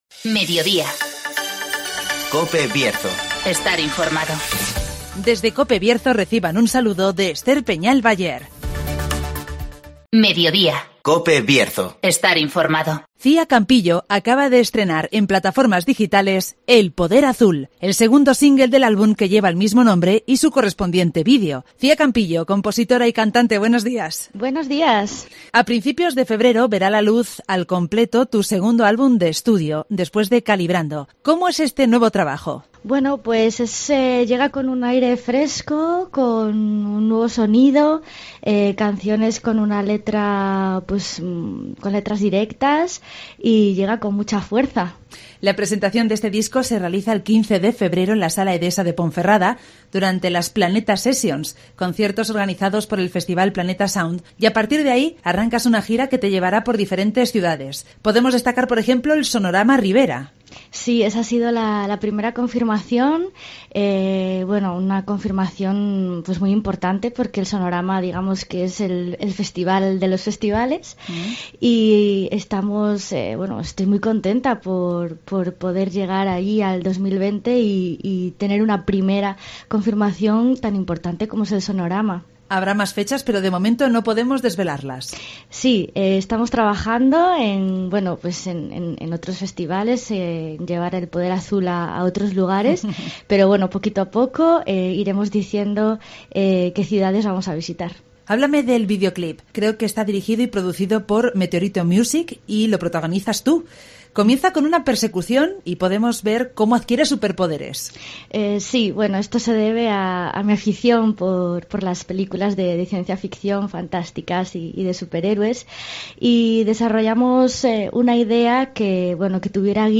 AUDIO: Repasamos la actualidad y realidad del Bierzo. Espacio comarcal de actualidad,entrevistas y entretenimiento.